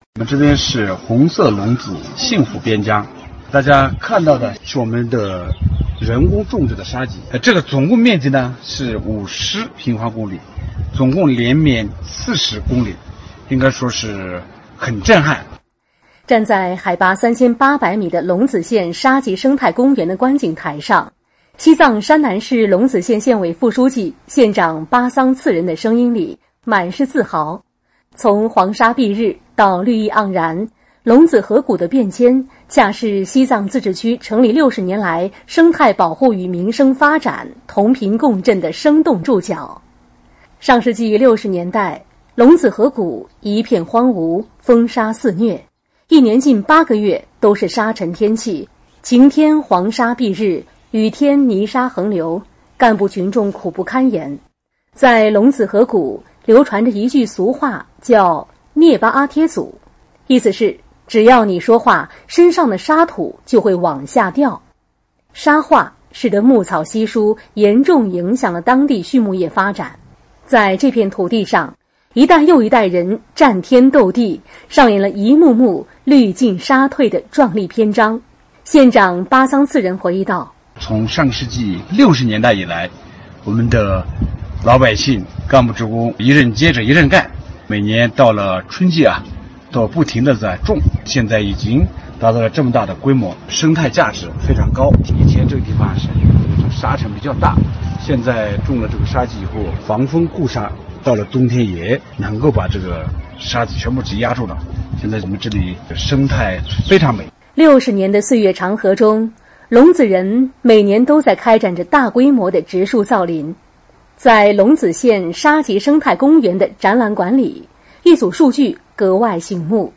站在海拔3800米的隆子县沙棘生态公园的观景台上，西藏山南隆子县县委副书记、县长巴桑次仁满是自豪。